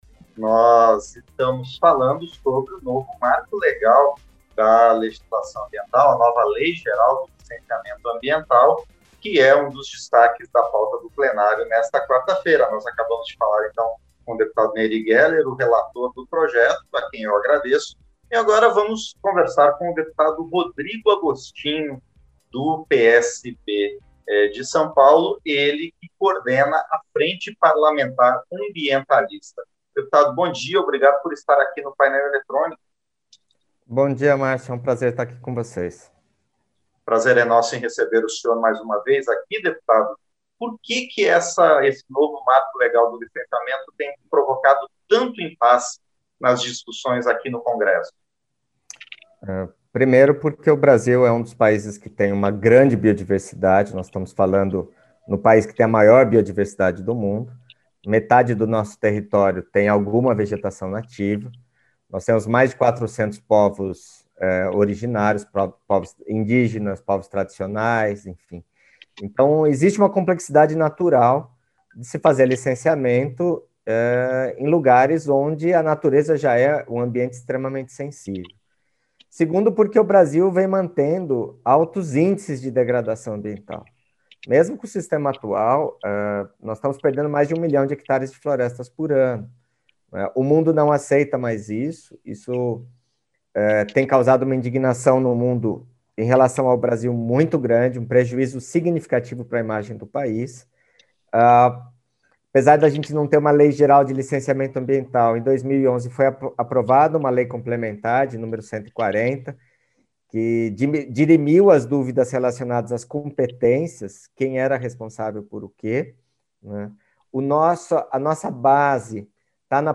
Entrevista - Dep. Rodrigo Agostinho (PSB-SP)